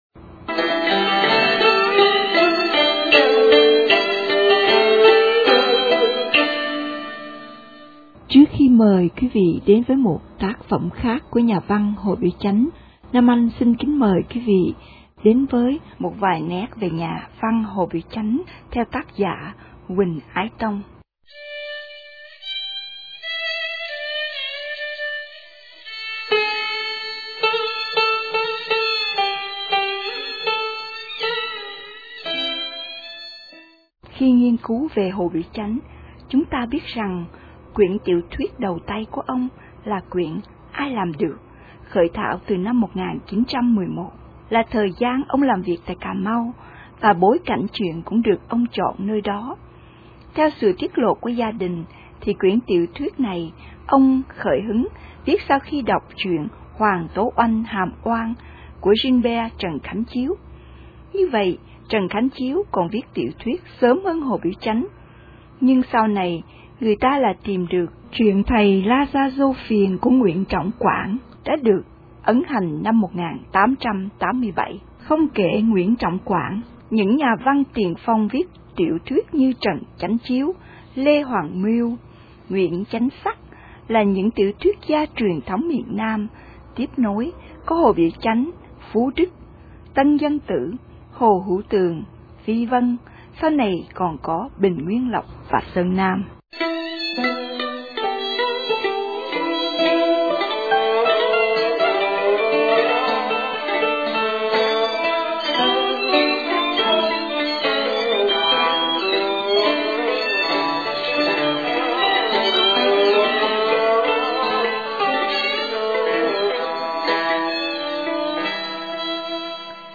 Phụ lục: - Xin mời nghe b�i đọc về Hồ Biểu Ch�nh trong Tiết 5: Tiểu Thuyết trong t�c phẩm